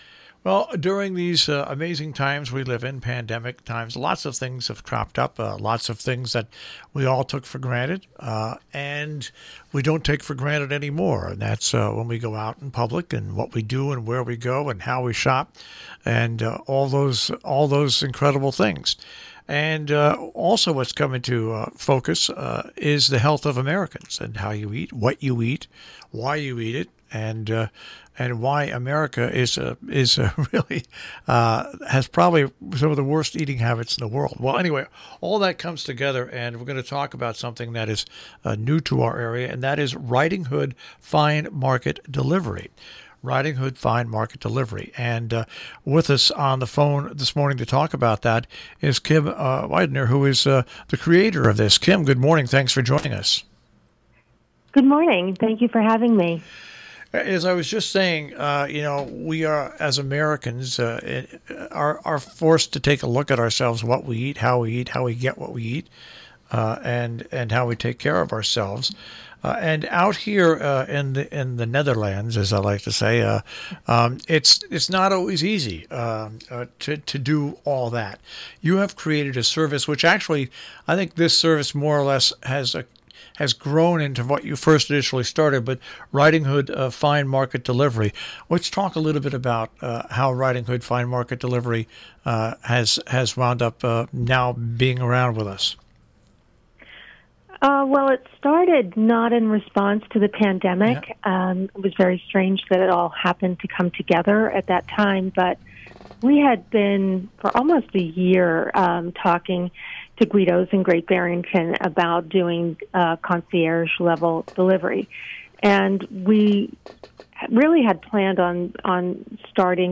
ROBIN HOOD RADIO INTERVIEWS